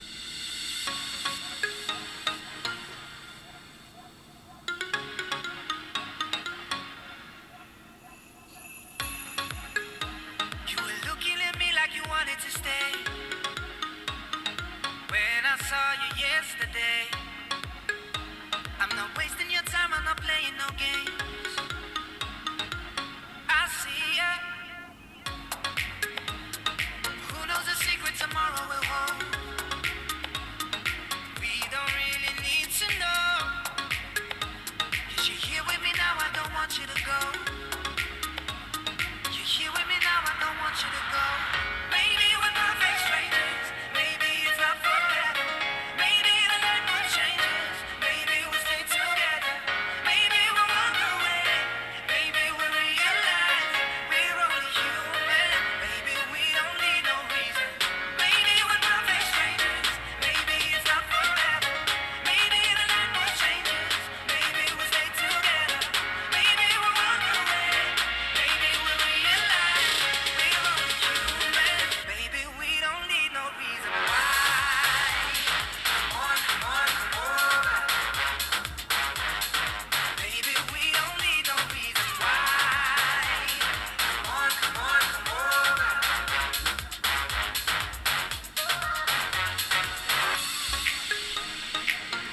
参考までに、ヘッドホンから聞こえるサウンドを録音したデータを掲載する。
▼ヘッドホンにマイクを近接させて録音。
※あくまで低音・高音の強さをなんとなく分かってもらうためのものであり、実際の聴こえ方とは異なるので注意。